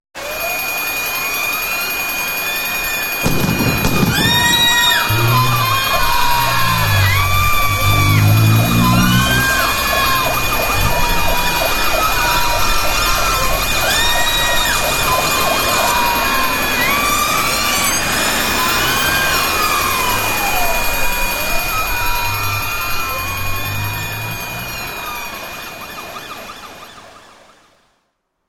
Здесь вы найдете тревожные сирены, звон разбитого стекла, шепот грабителей и другие эффектные аудиоэффекты.
Шум погони после ограбления банка